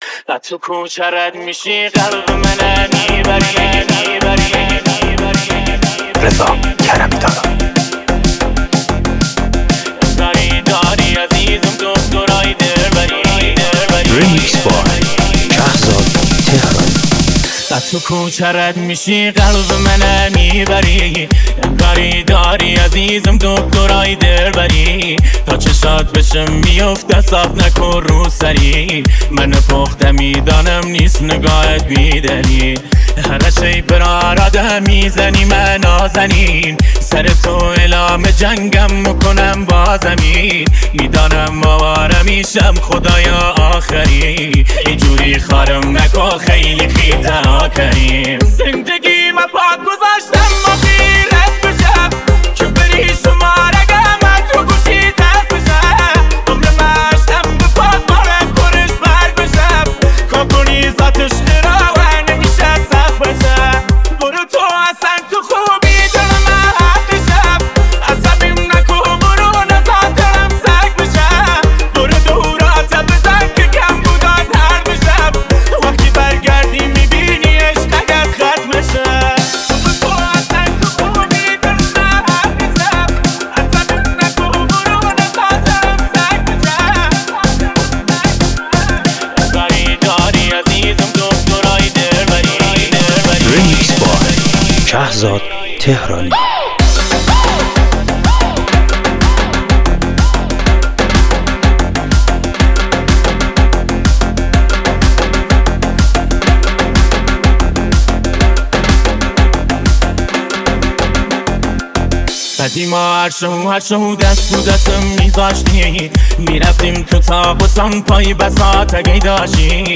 دانلود ریمیکس این آهنگ